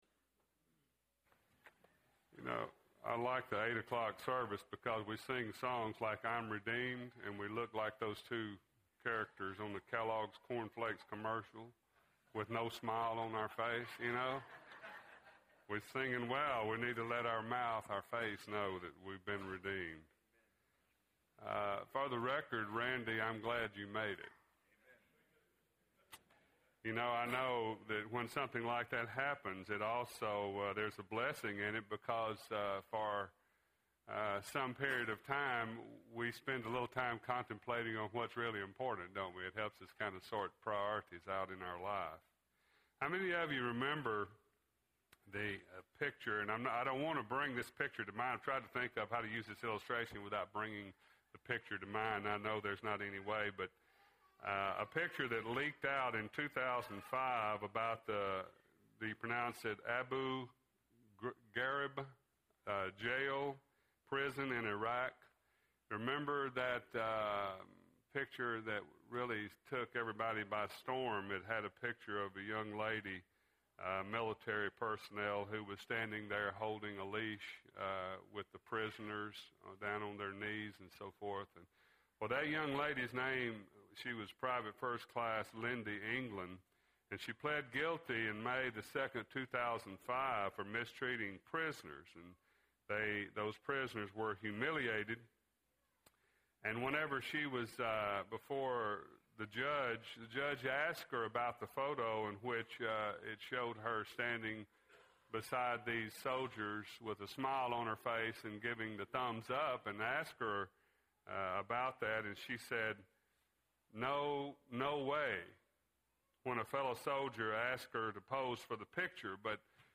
Peer Pressure – Bible Lesson Recording
Sunday AM Sermon